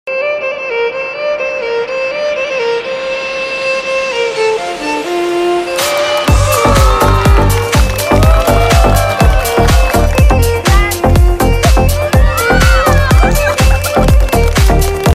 结婚场景.mp3